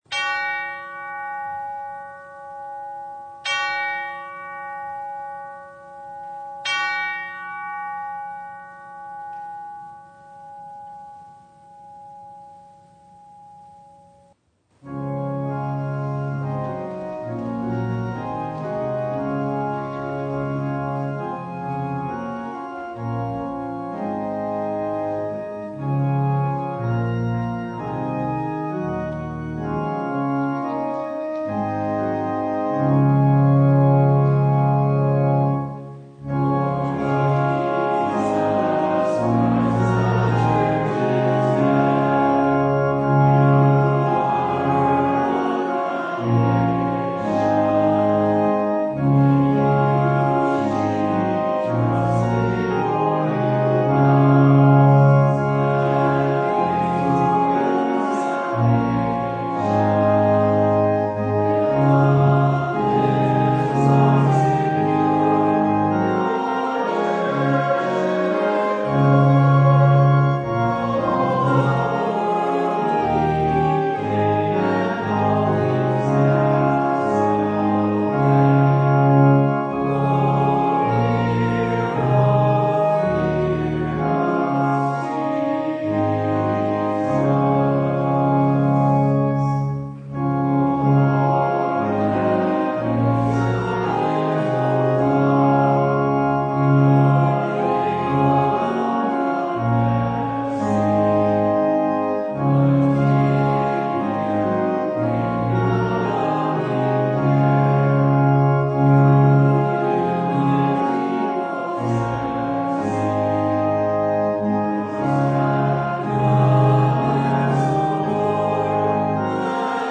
Service Type: Sunday
Download Files Bulletin Topics: Full Service « “Authority!”